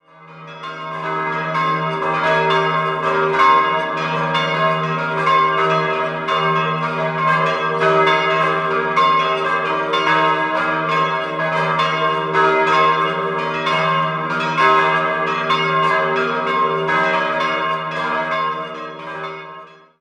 5-stimmiges erweitertes Salve-Regina-Geläute: es'-g'-b'-c''-es'' Die große Glocke wurde 1958 von Rudolf Perner gegossen, die mittlere stammt aus dem Jahr 1493 und die drei kleinen entstanden im Jahr 1948.